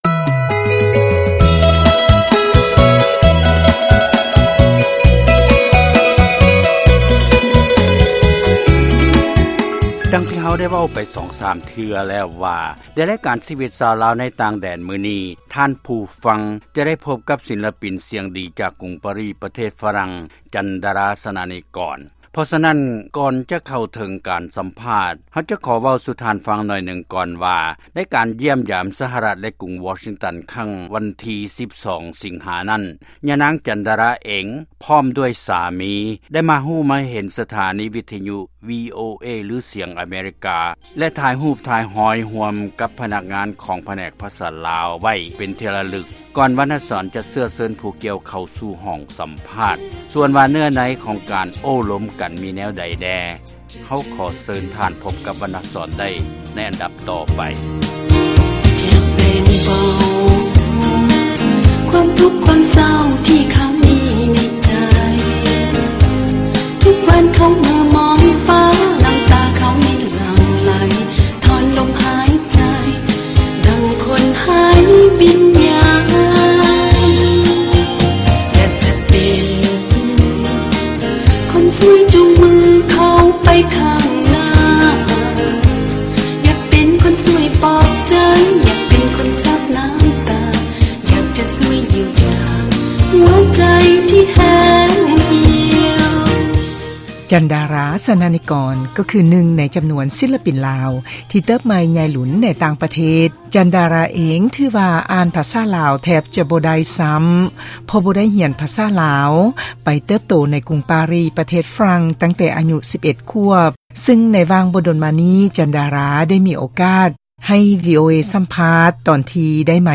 ການສໍາພາດກັບ